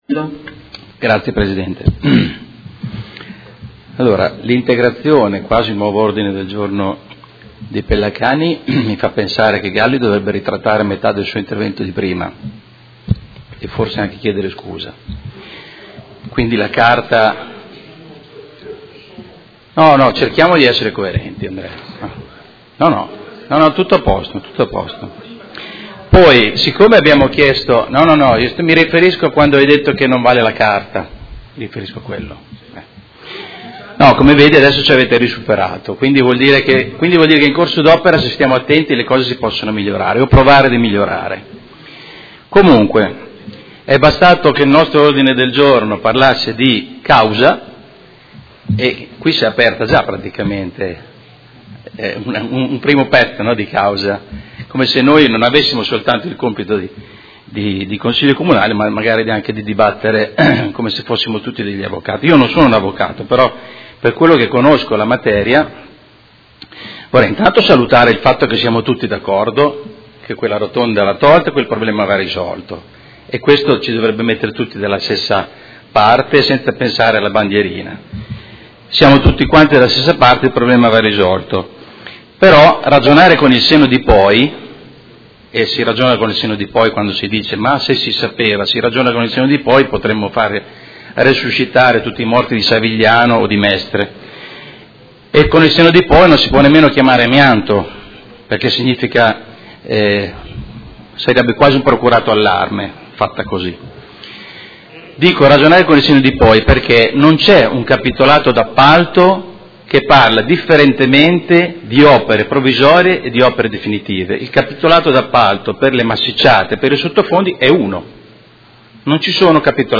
Seduta del 26/03/2018 Dibattito. Ordini del giorno Rotatoria di via Emilia Est